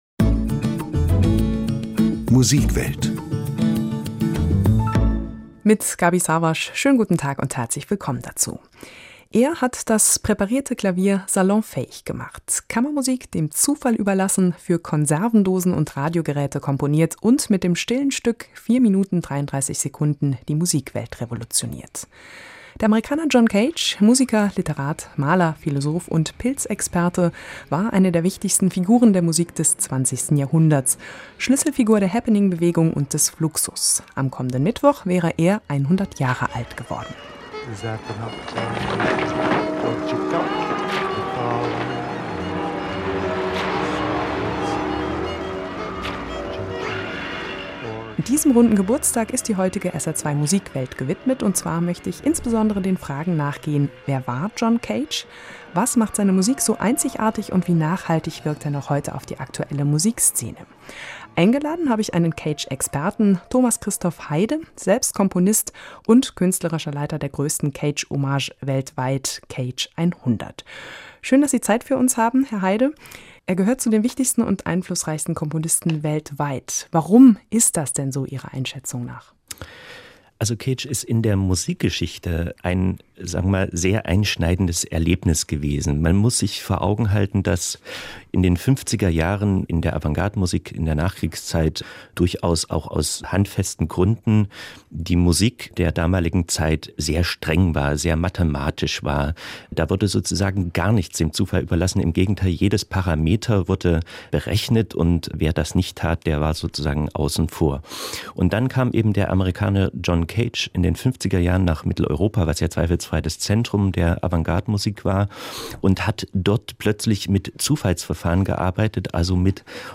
Saarlaendischer-Rundfunk-2-_Musikwelt_-Interview-zu-CAGE100.mp3